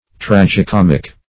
Tragi-comic \Trag`i-com"ic\, Tragi-comical \Trag`i-com"ic*al\,